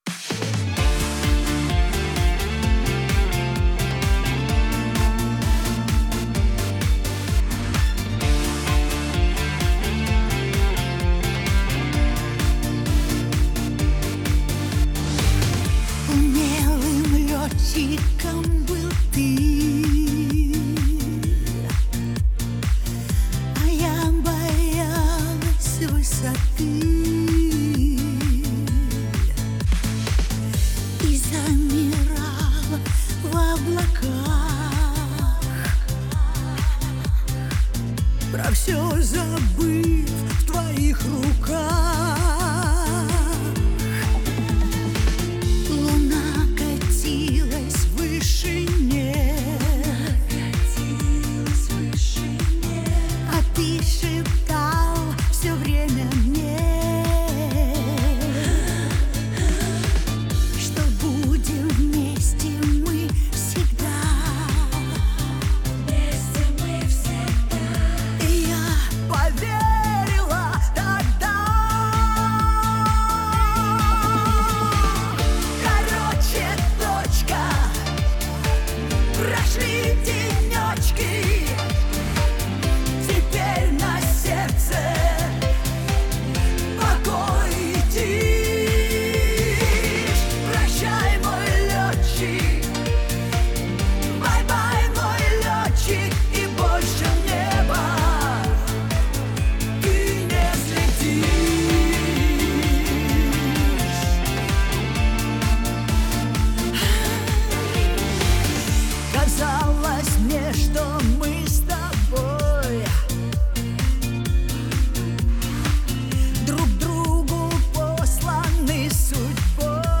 Жанр: Pop, Russian Pop